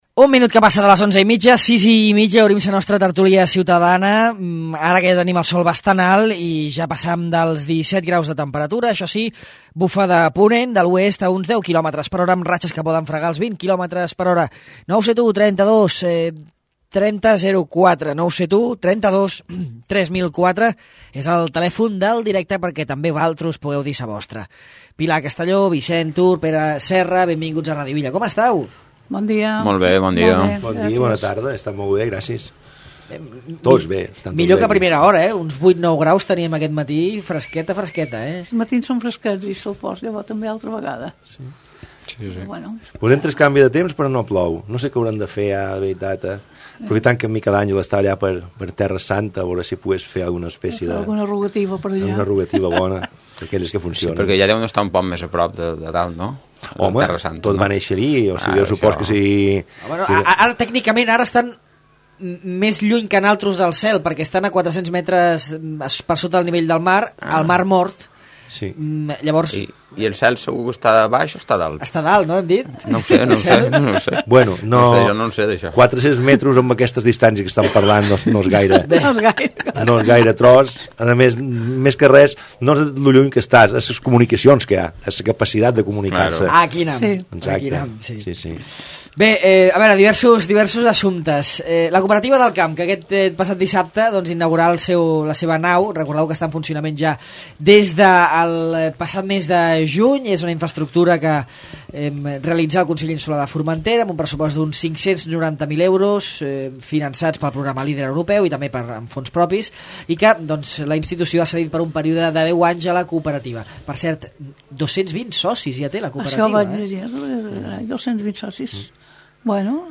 La Tertúlia del dimecres